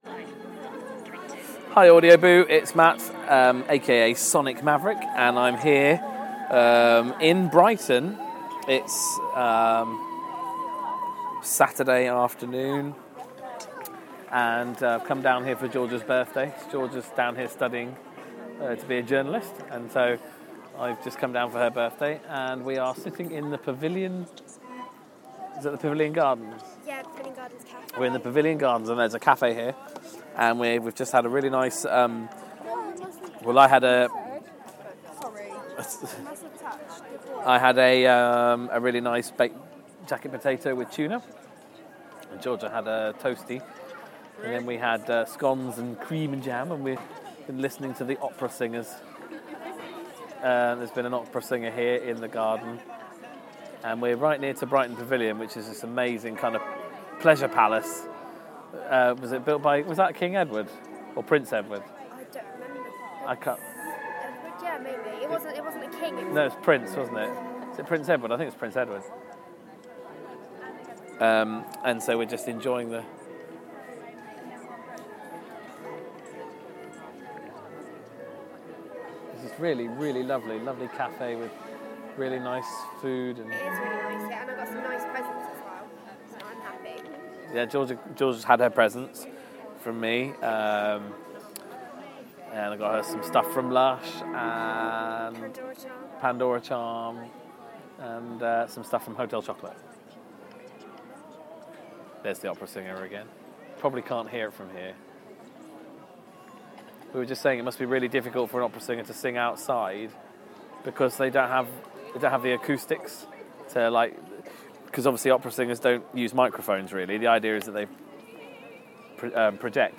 Pavilion gardens cafe in Brighton